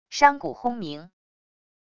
山谷轰鸣wav音频